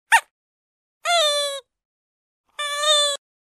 Звуки детских игрушек